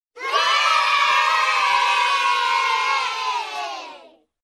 Play เสียงเด็ก เย้ - SoundBoardGuy